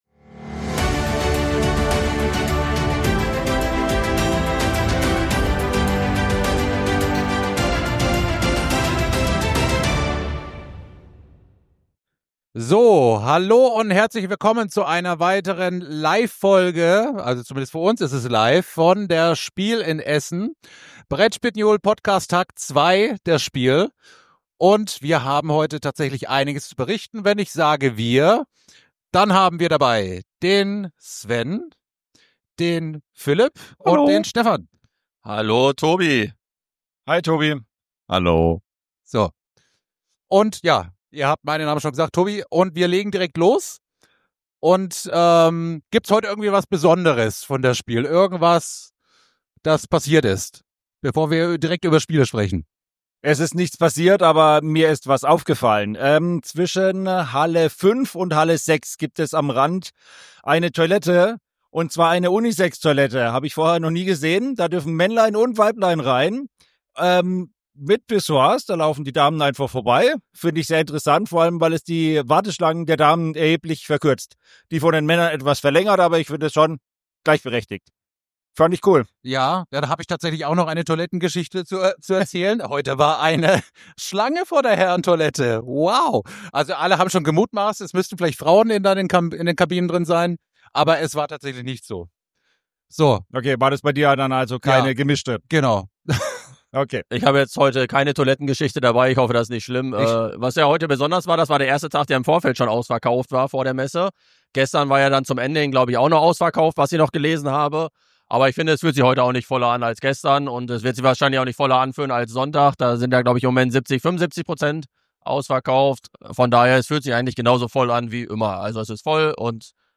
In dieser Episode berichten wir live vom zweiten Tag der Spielmesse in Essen.